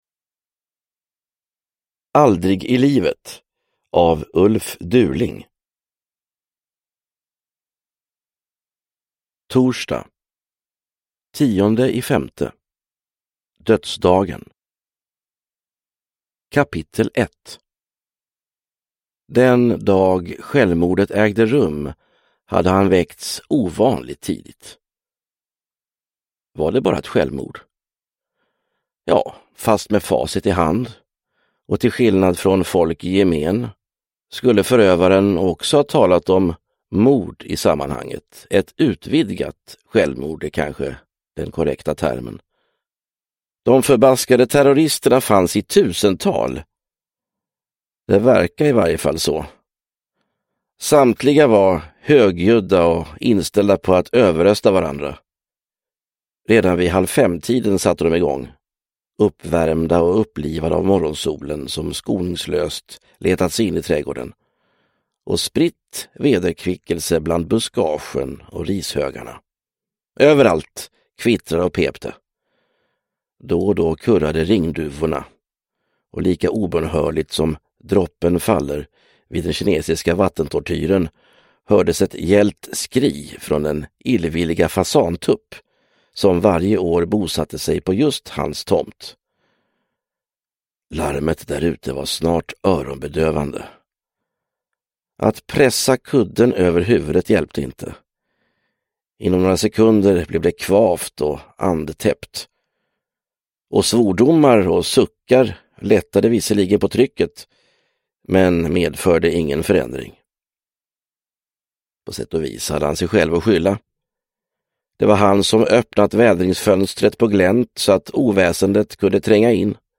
Aldrig i livet – Ljudbok – Laddas ner